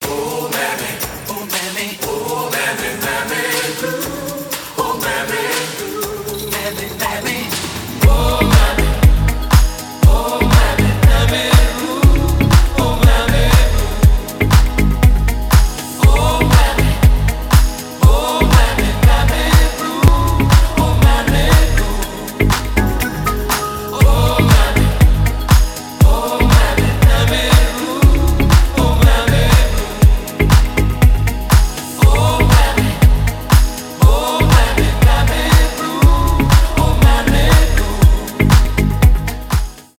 deep house , retromix